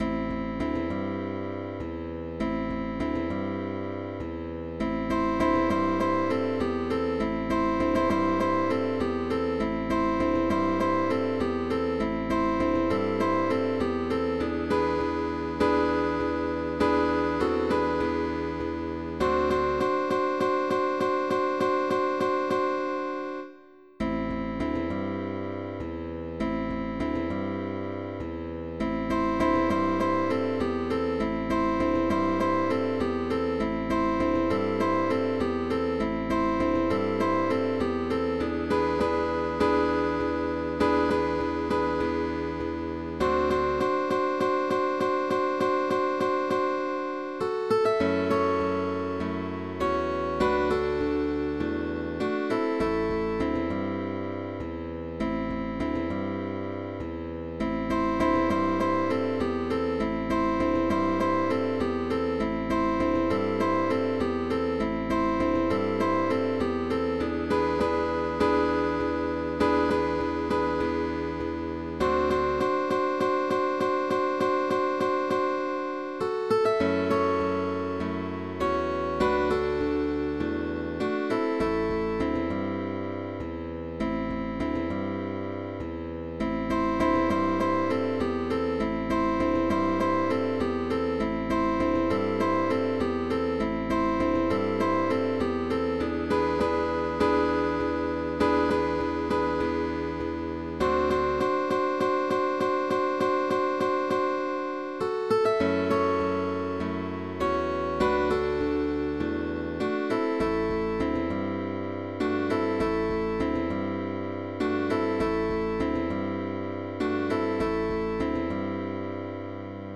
Chords of two and three notes. With optional bass.